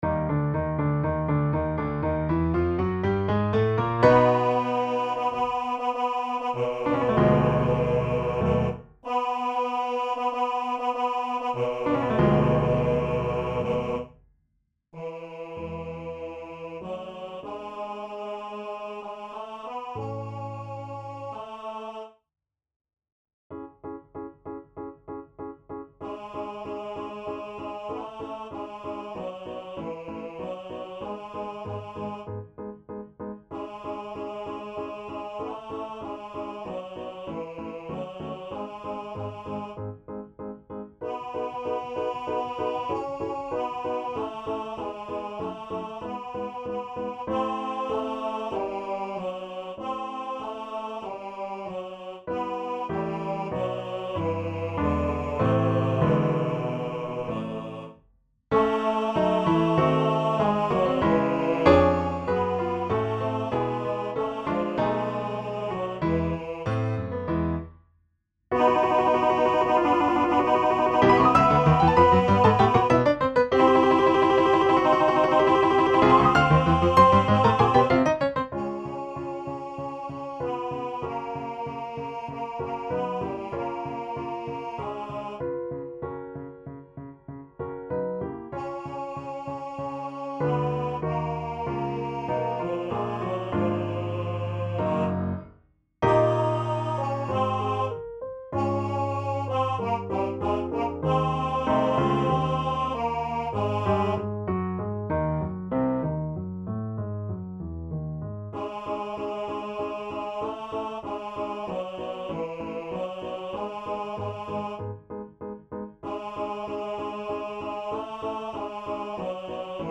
SINGEN: Lieder und Arien für Bass/Bariton